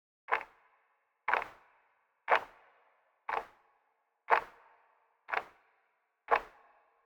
Tick.ogg